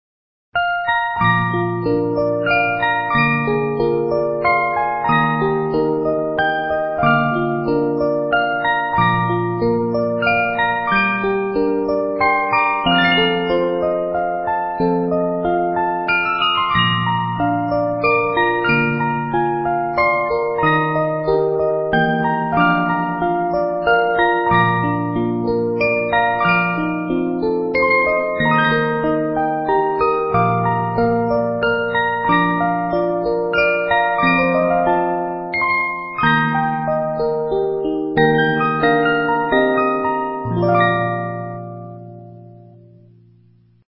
Music box Sound Bites of 50 note movements